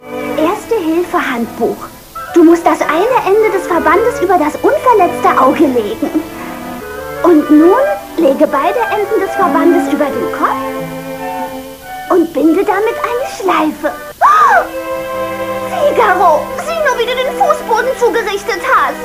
Zweimal konnte ich die Dame bislang ausmachen, anscheinend mit unterschiedlichen Sprecherinnen.